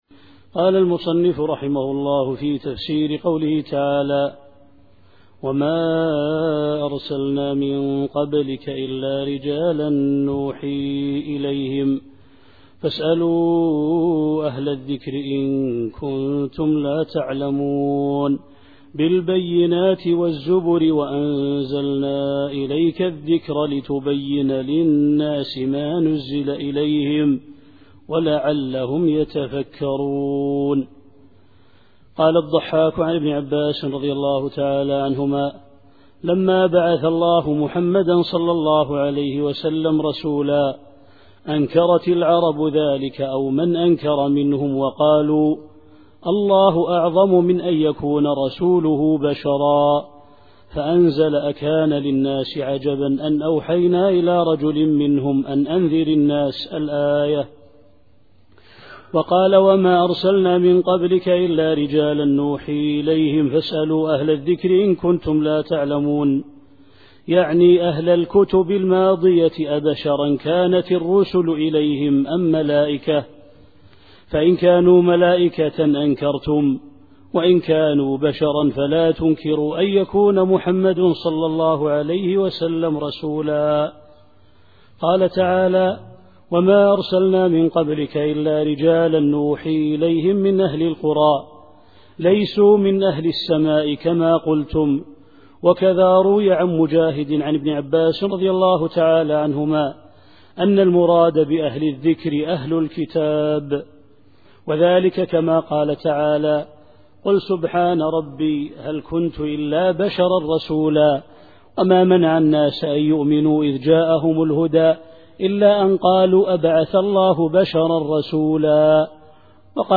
التفسير الصوتي [النحل / 43]